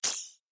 pickup_star.ogg